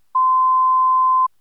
ettusentrettiofem.wav